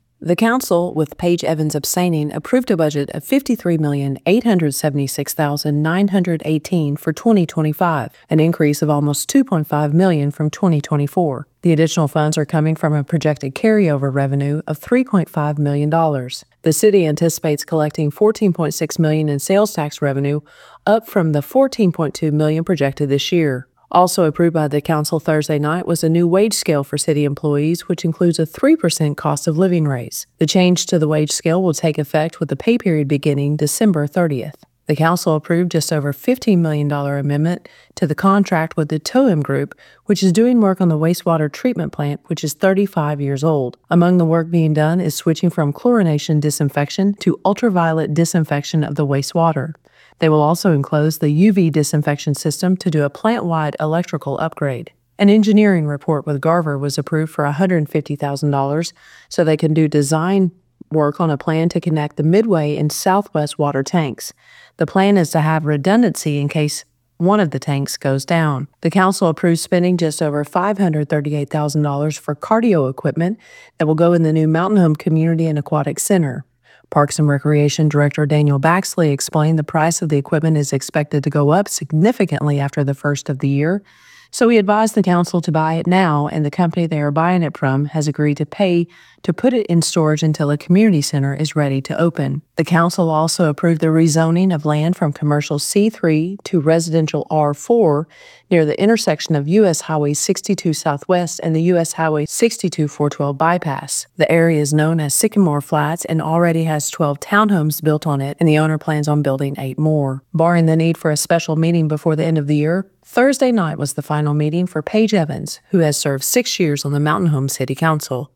December 20, 2024 5:34 am Local News, WireReady